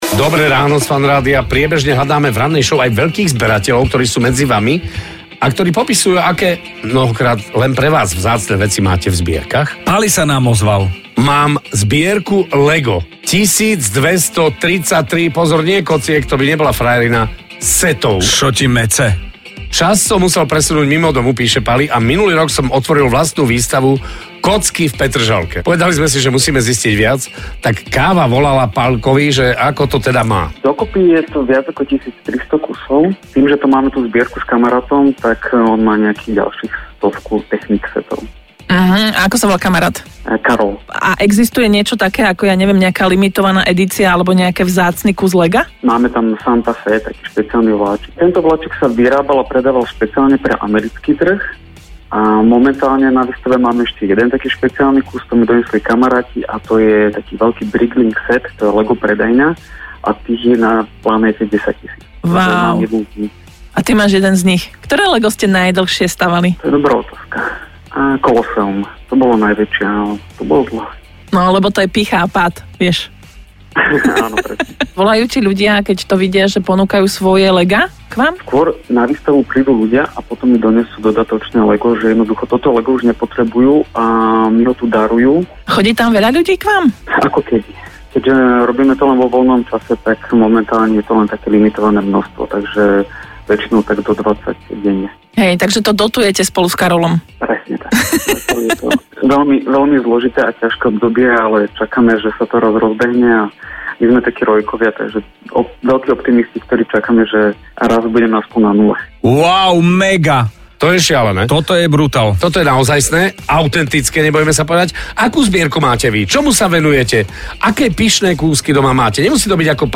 3. Rozhovor
FUNRADIO-vystavakocky.mp3